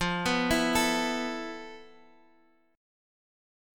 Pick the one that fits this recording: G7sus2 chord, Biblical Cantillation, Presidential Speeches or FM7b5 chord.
FM7b5 chord